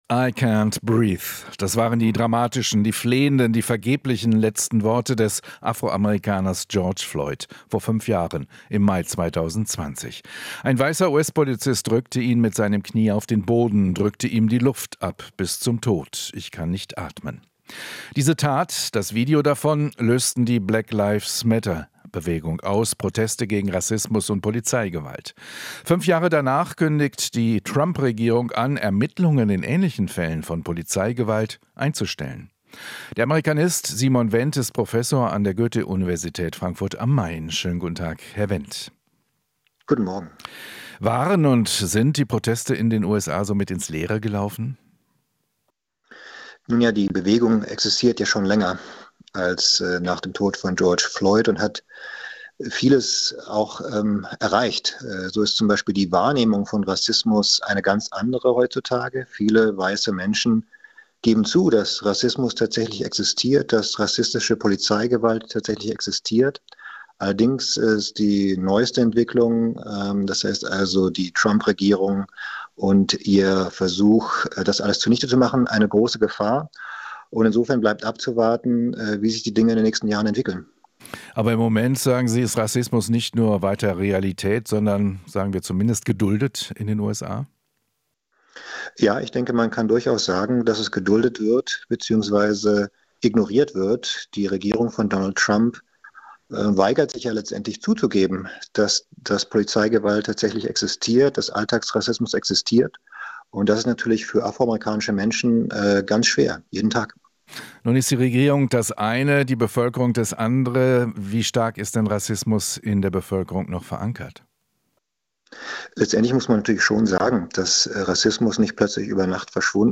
Interview - Amerika-Experte: Situation für Afro-Amerikaner unter Trump sehr schwer